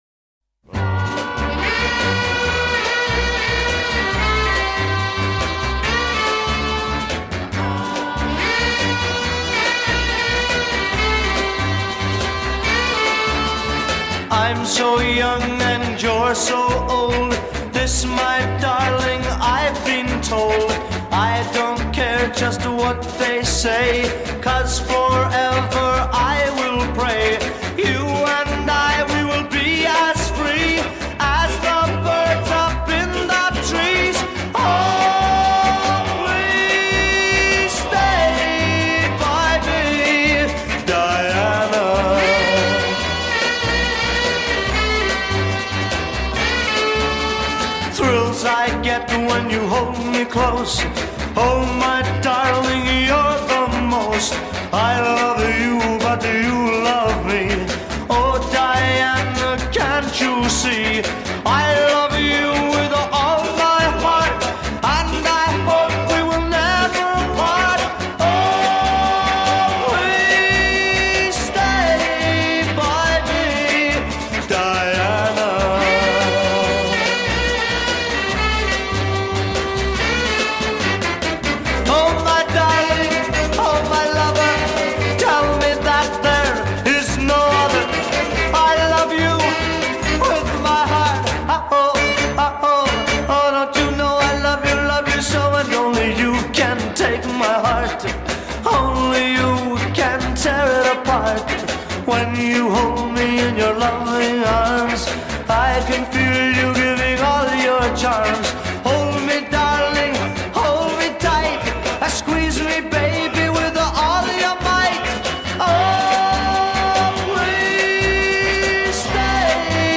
Pop, Jazz